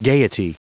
Prononciation du mot gaiety en anglais (fichier audio)
Prononciation du mot : gaiety